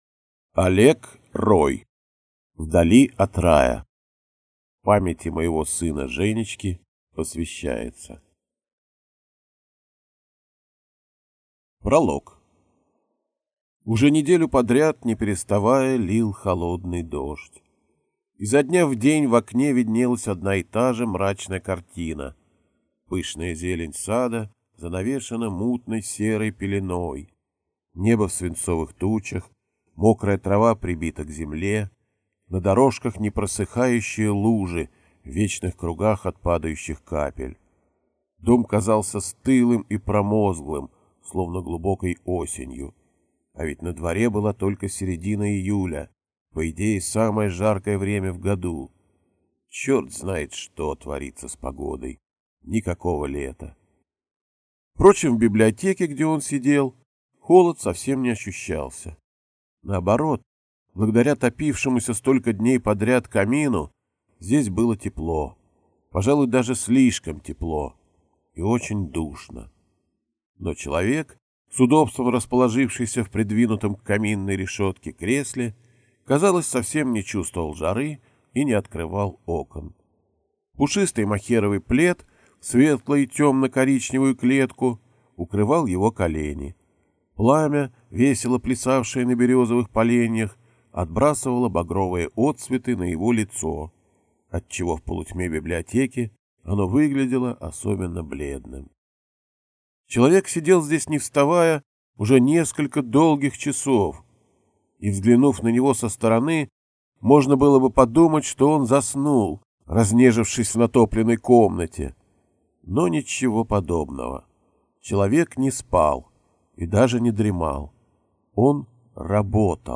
Аудиокнига Вдали от рая | Библиотека аудиокниг